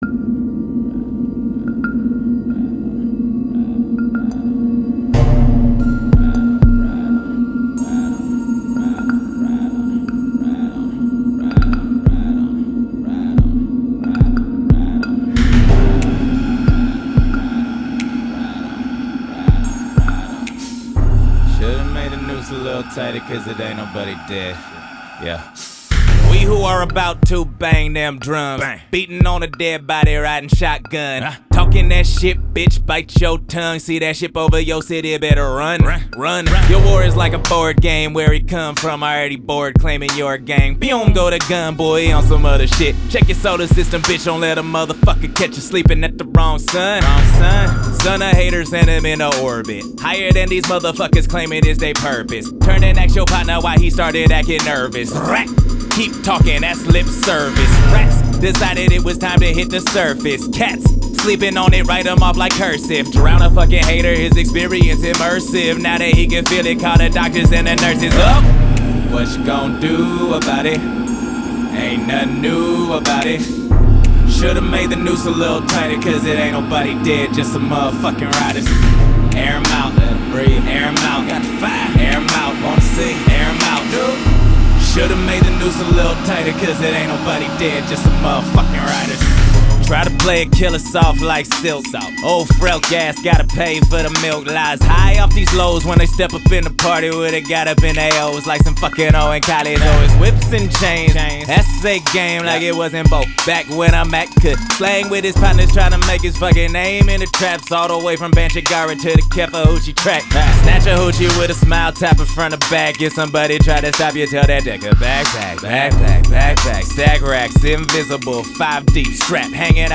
noise rap/industrial hip hop